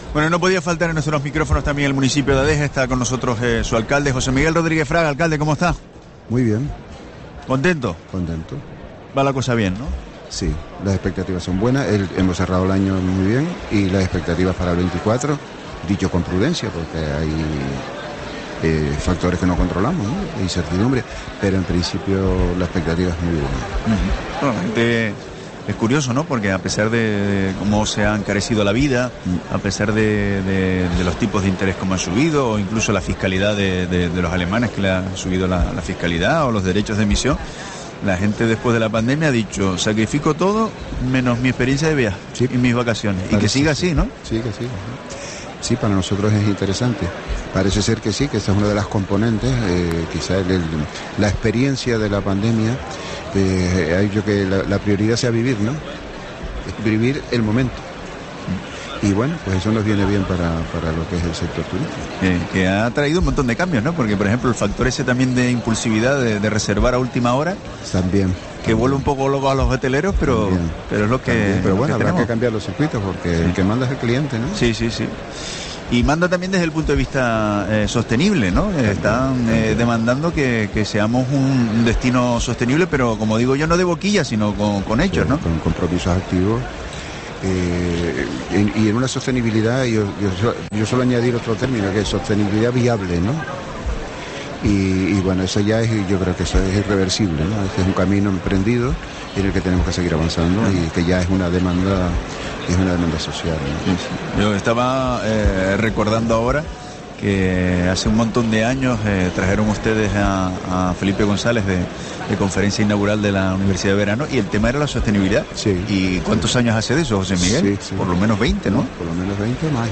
Entrevista a José Miguel Rodríguez Fraga, alcalde de Adeje, en FITUR 2024